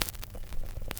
Record Noise2.WAV